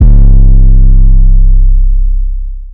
[808] (10) saint.wav